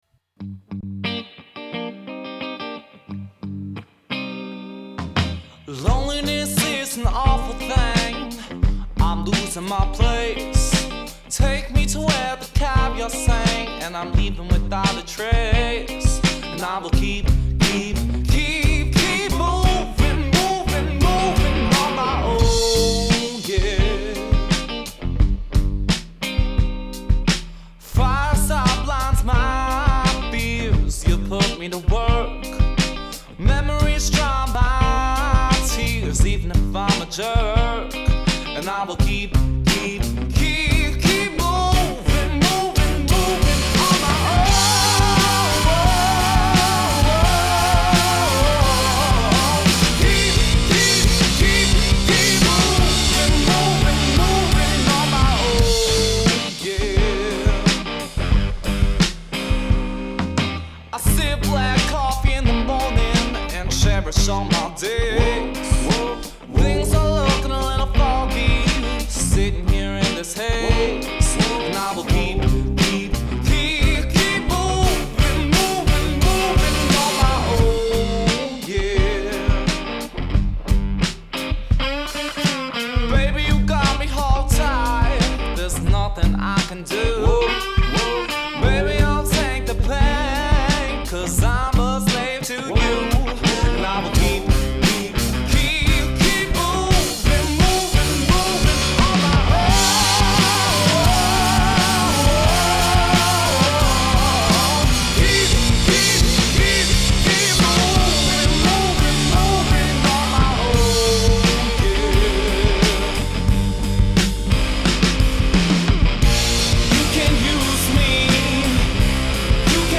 Genre: Rock 'n' Roll, Soul